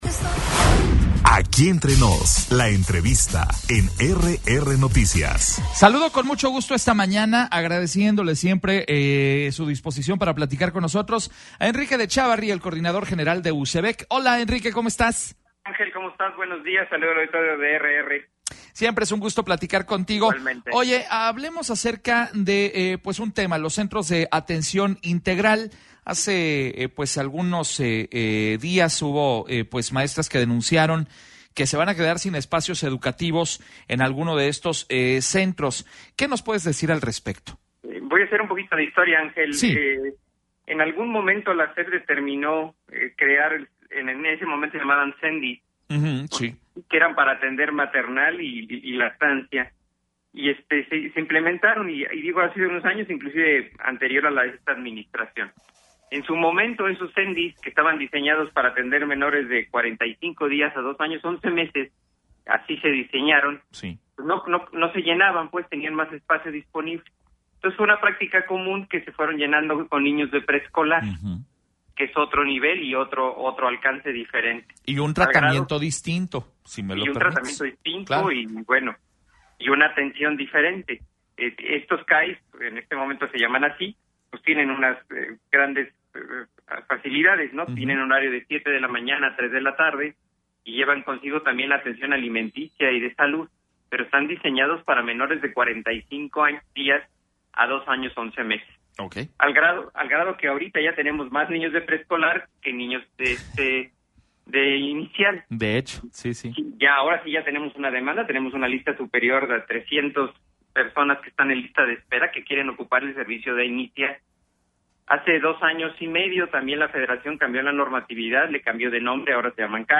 En entrevista exclusiva